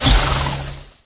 Media:jessie_atk_01.wav 攻击音效 atk 初级及以上形态攻击音效
Jessie_atk_01.wav